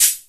DB - Percussion (9).wav